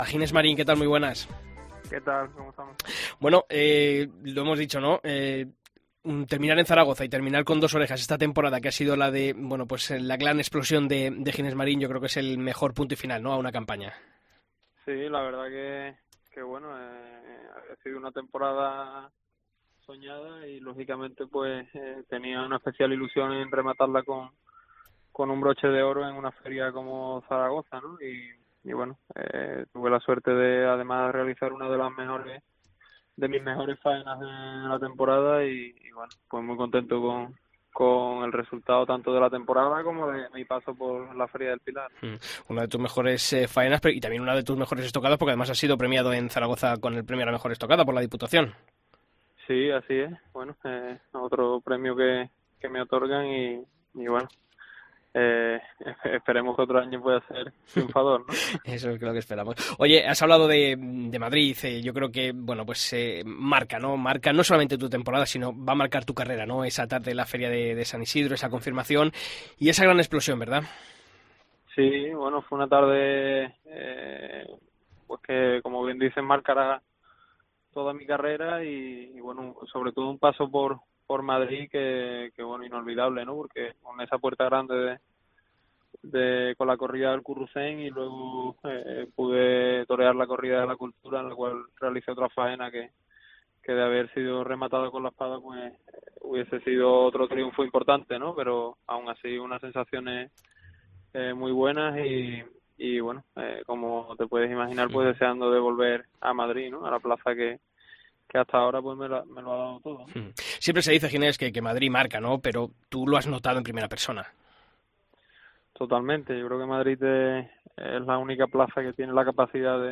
Ginés Marín atendía a El Albero desde París y definía su año como “mi temporada soñada”.